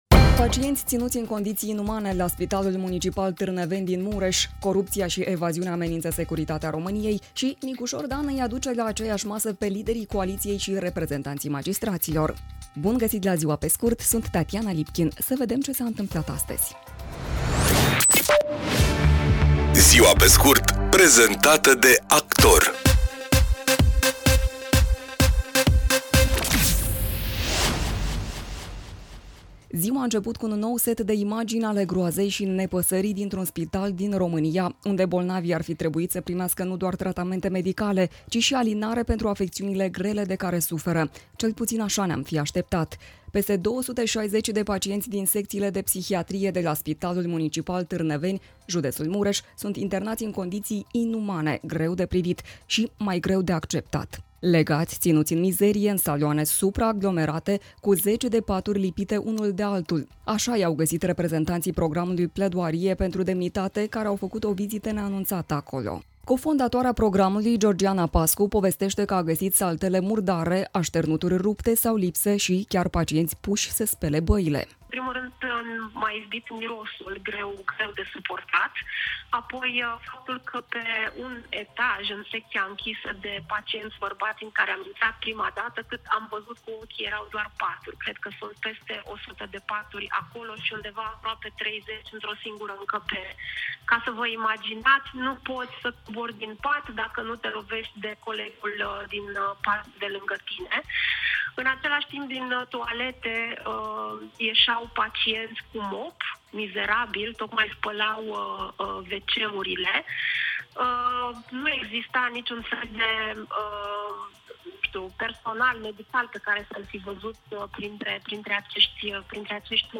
„Ziua pe scurt” este un podcast zilnic de actualitate care oferă, în doar zece minute, o sinteză a principalelor cinci știri ale zilei. Formatul propune o abordare prietenoasă, echilibrată și relaxată a informației, adaptată publicului modern, aflat mereu în mișcare, dar care își dorește să rămână conectat la cele mai importante evenimente.